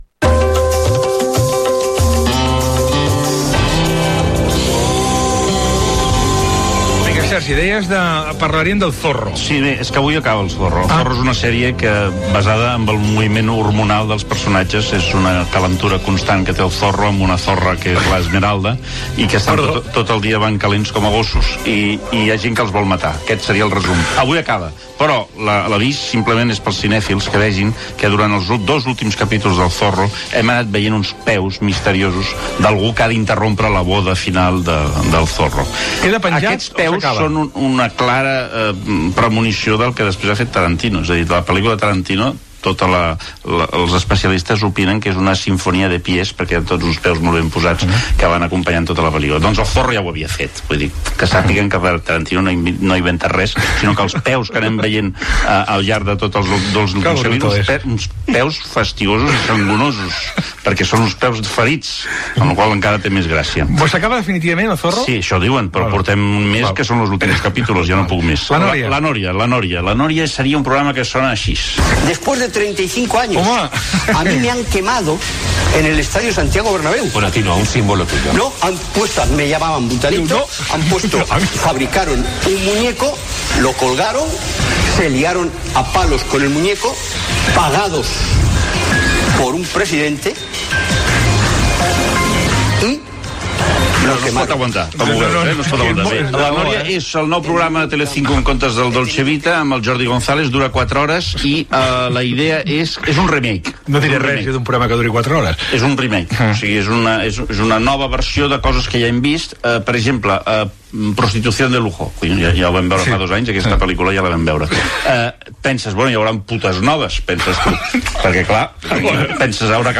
diàleg amb el President Maragall (imitació feta per Queco Novell)
Info-entreteniment